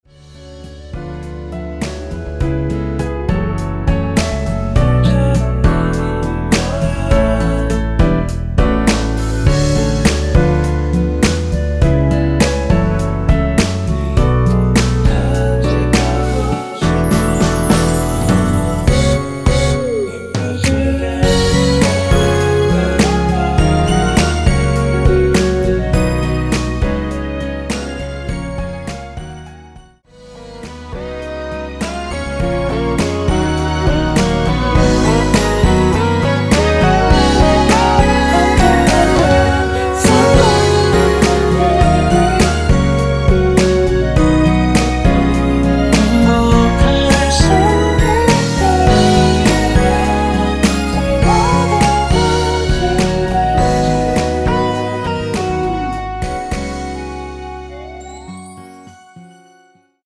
원키에서(-2)내린 멜로디와 코러스 포함된 MR입니다.(미리듣기 확인)
앨범 | O.S.T
앞부분30초, 뒷부분30초씩 편집해서 올려 드리고 있습니다.
중간에 음이 끈어지고 다시 나오는 이유는